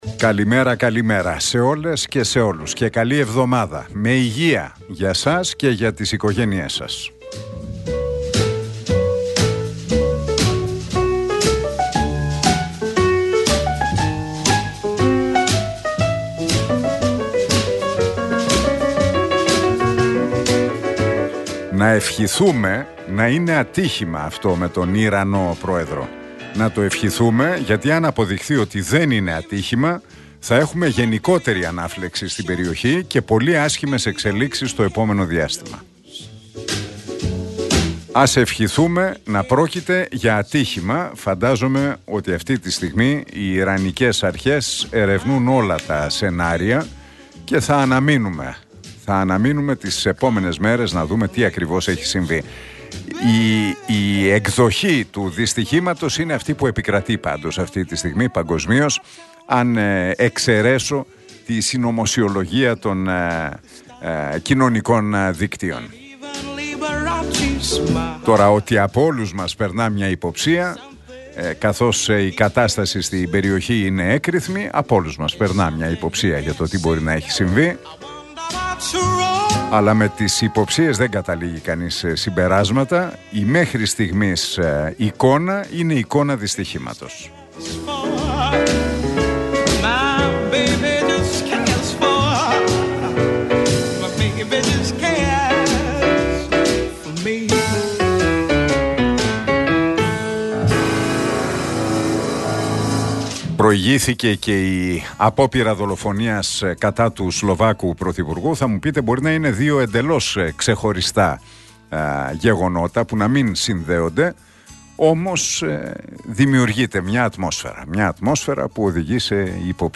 Ακούστε το σχόλιο του Νίκου Χατζηνικολάου στον RealFm 97,8, την Δευτέρα 20 Μαΐου 2024.